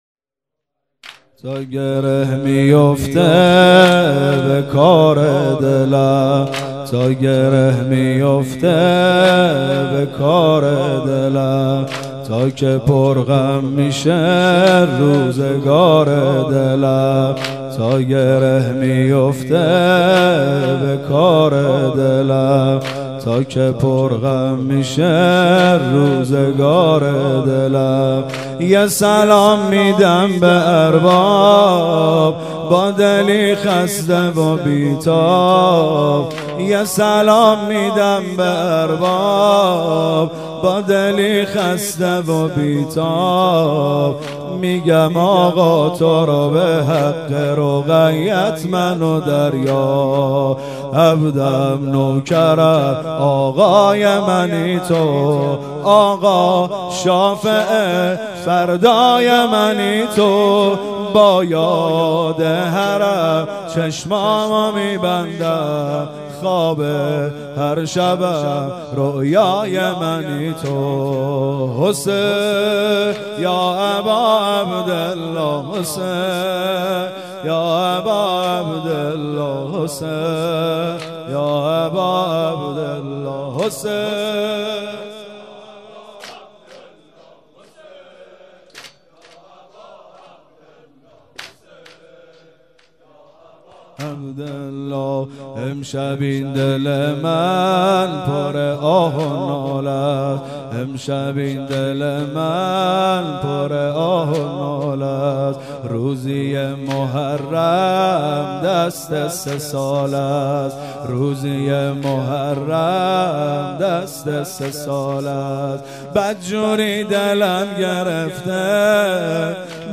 واحد زمینه شور